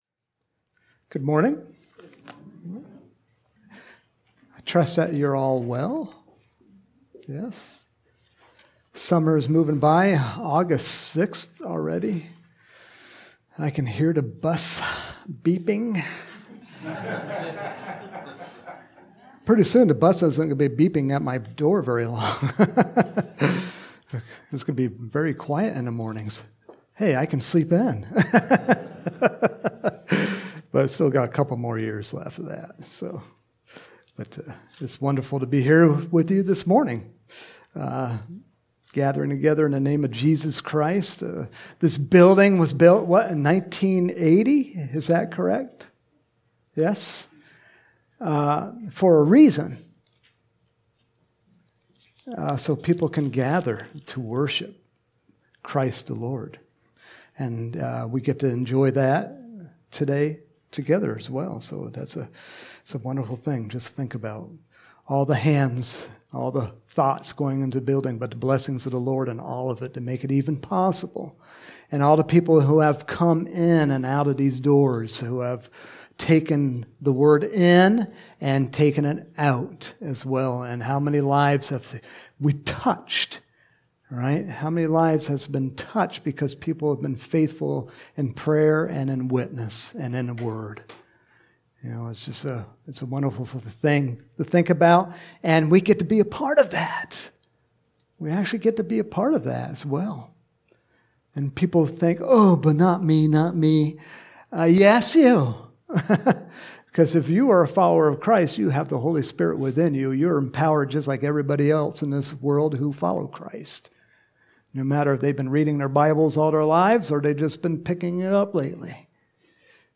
Home › Sermons › August 6, 2023
Sunday Morning Sermon